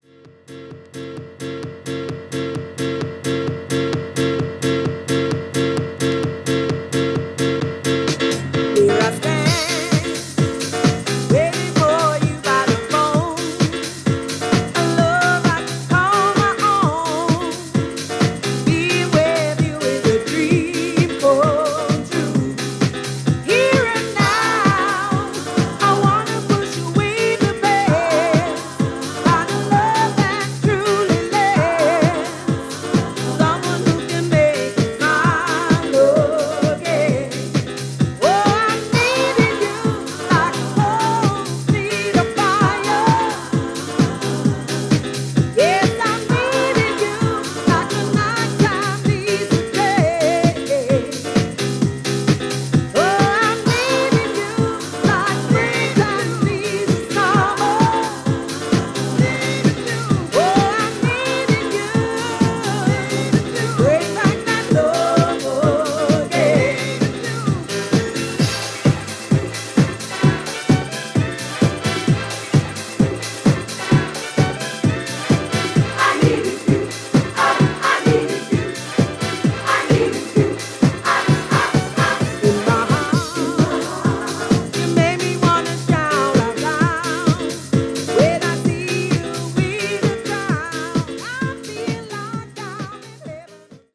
Backing Vocals
Keyboards
Drums